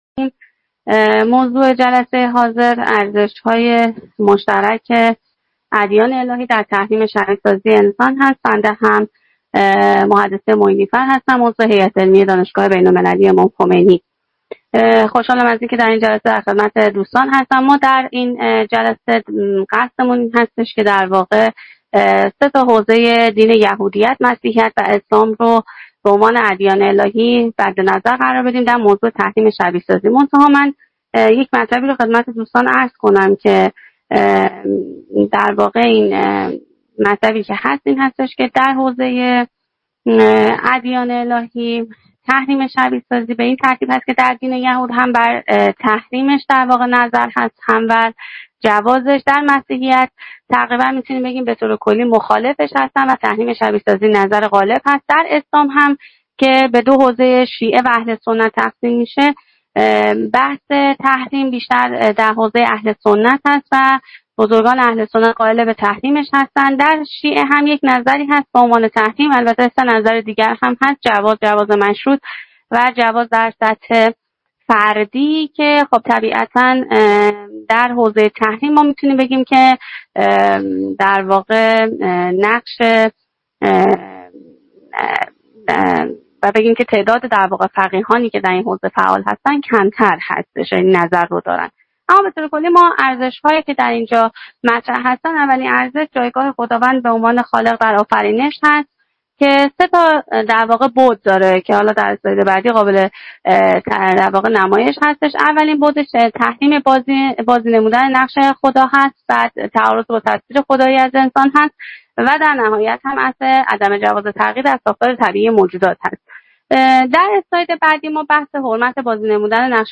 صوت نشست علمی ارزش های مشترک ادیان الهی در تحریم شبیه سازی انسان